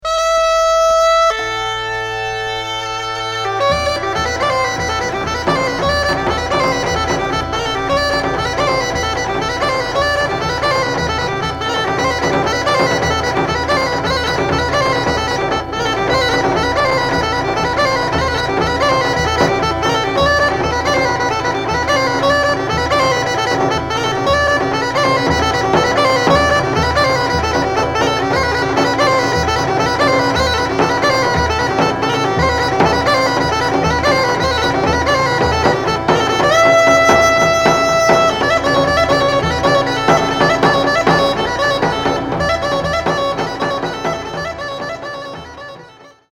憂色を帯びたしなやかな声を聴かせる土着民謡や、素朴な響きに満ちた民族楽器の調べなど、生活の中の庶民的な音楽風景を真空パック。
キーワード：現地録り　ブルガリア 　バルカン